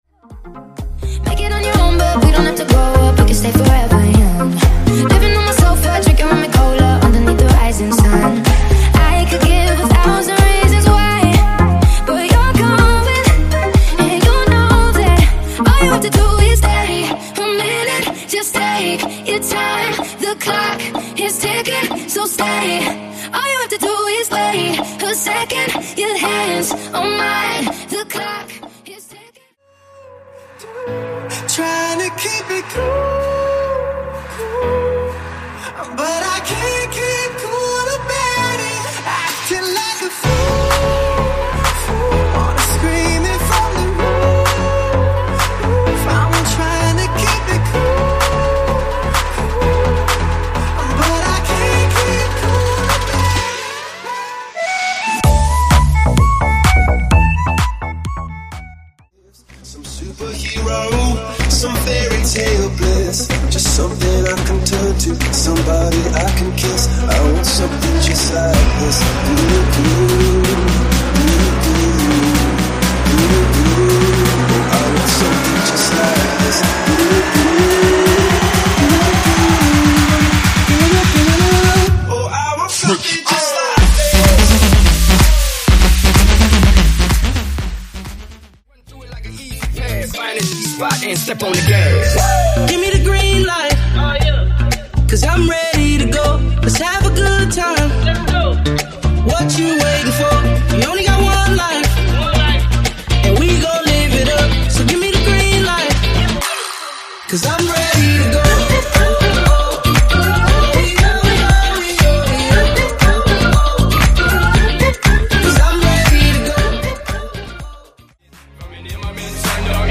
Genres: MASHUPS , R & B , TOP40